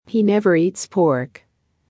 【ややスロー・スピード】